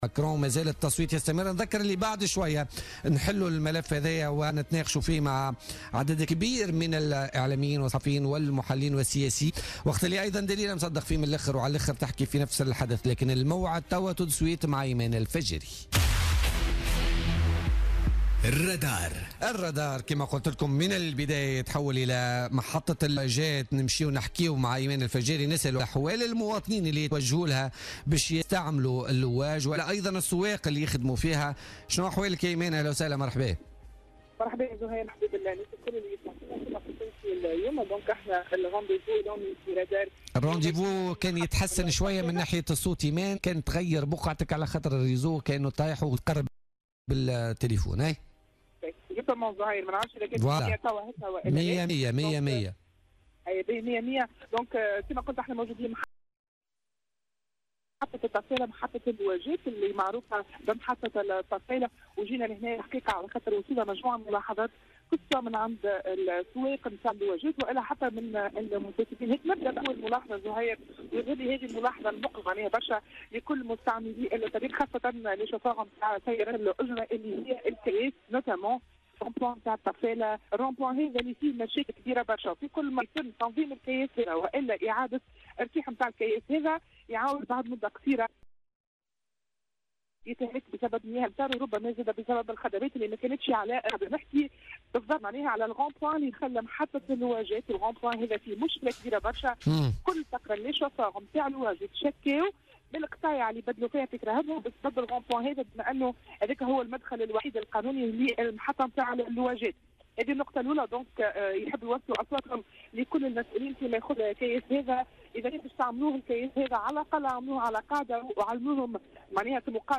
تحول فريق الرادار اليوم الخميس إلى محطة "اللواج" في الطفالة للإطلاع على وضعيتها بعد ورود عدة ملاحظات من مستعملي هذه المحطة سواء من أصحاب سيارات الأجرة أو المسافرين.